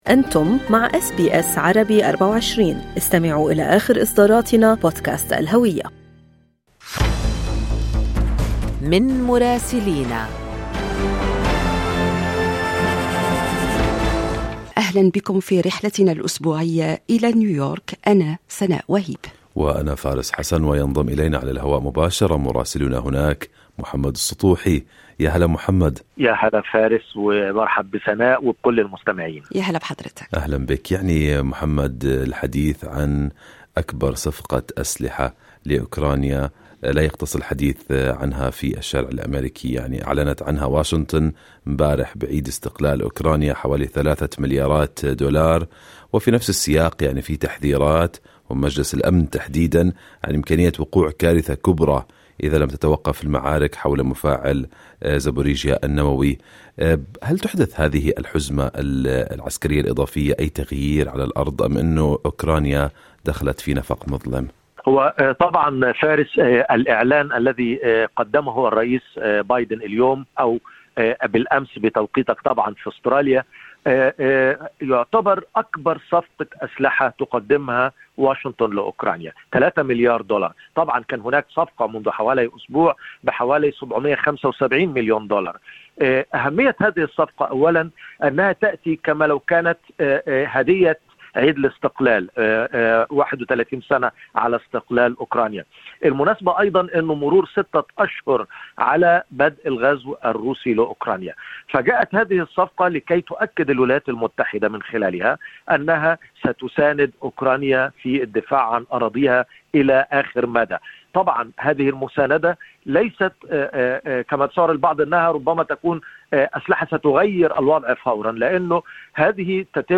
من مراسلينا: أخبار الولايات المتحدة الأمريكية في أسبوع 25/8/2022